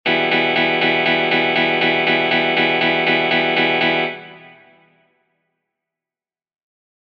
2- Achtelnote: Wechselschlag
Wir greifen einen E-Moll-Akkord und schlagen die Gitarrensaiten im Wechselschlag.